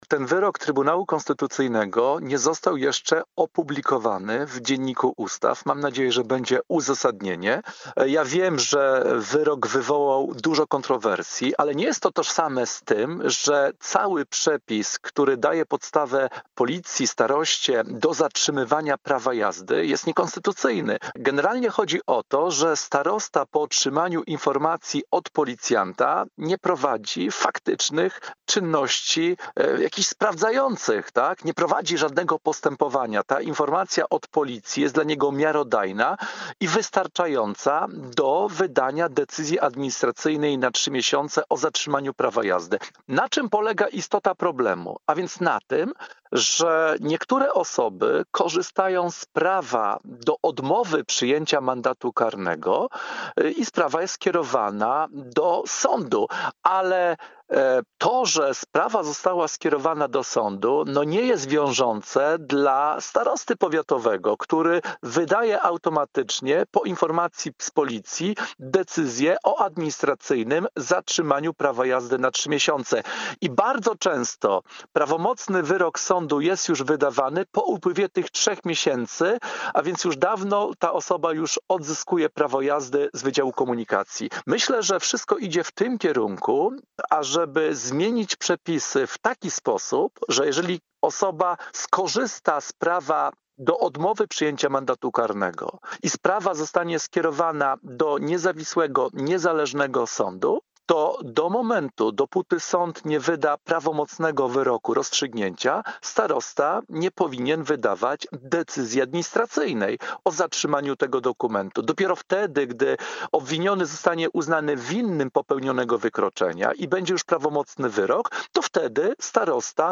Wypowiedź eksperta do spraw bezpieczeństwa ruchu drogowego